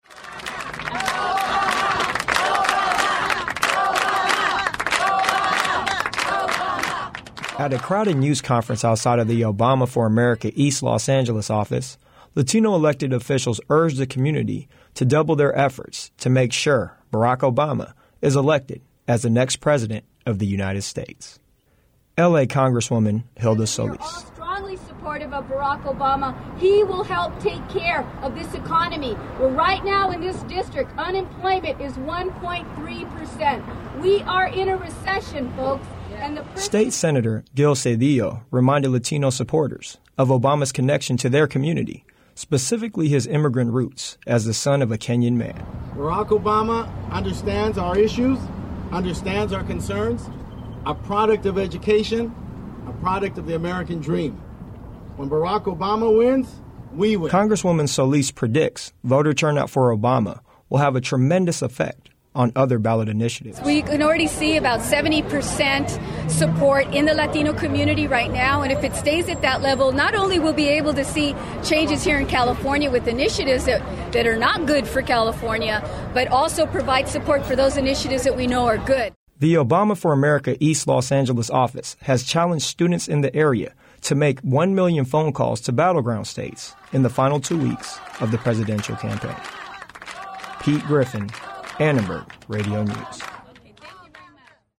Latino elected officials held a news conference in East Los Angeles to gather support from the Latino community for presidential hopeful Barack Obama. The event was held outside of the Obama for East Los Angeles Headquarters on East Cesar Chavez Avenue.